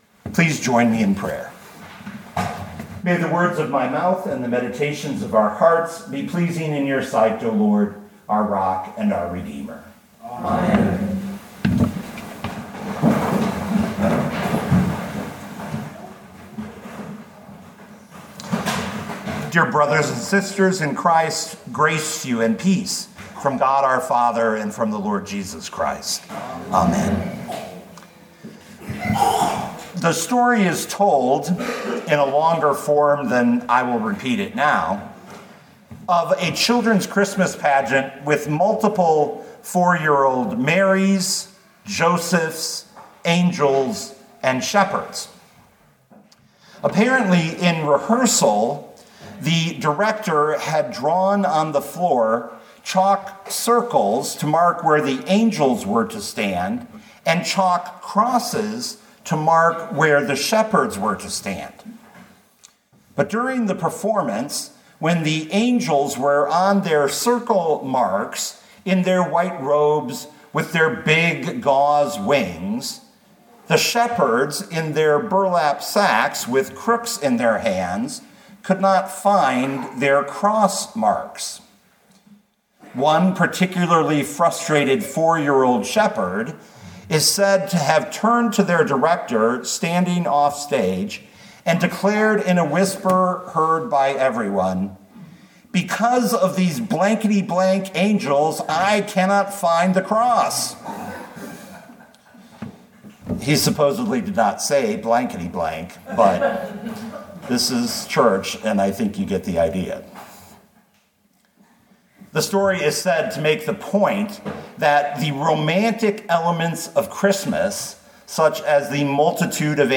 2024 John 1:1-18 Listen to the sermon with the player below, or, download the audio.